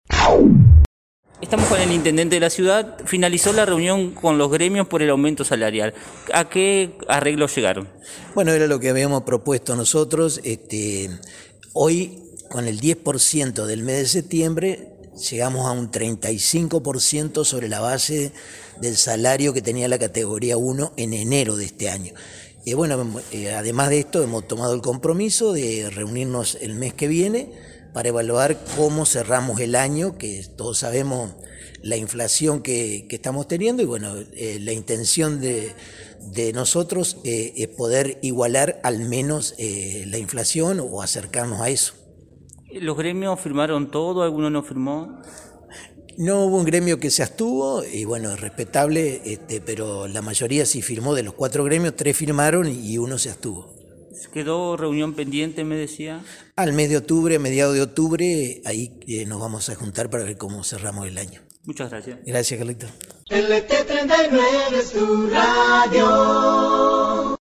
Tras la reunión el intendente dialogó con LT39 y comentó: “Con este 10% en el mes de septiembre llegamos a un 35% sobre la base del salario que tenía la categoría 1 en enero de este año”.
Intendente-Paritarias-web.mp3